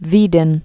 (vēdĭn)